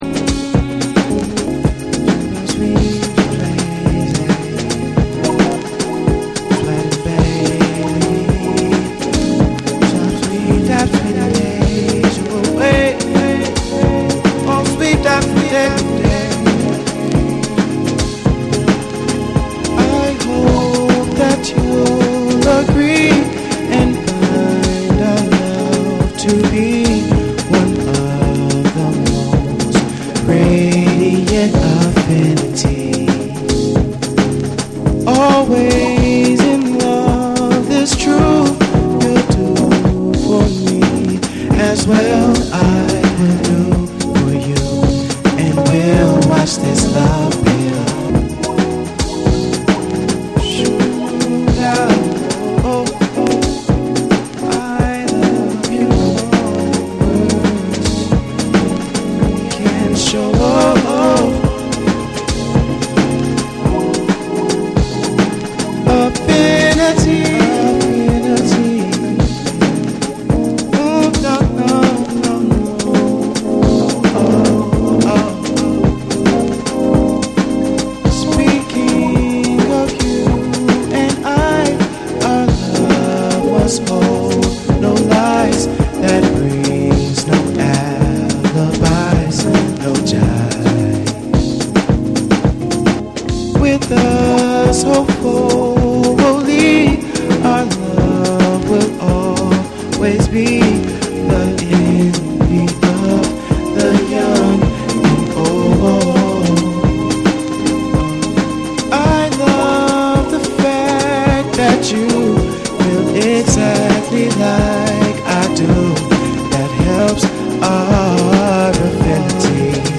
BREAKBEATS / RE-EDIT / MASH UP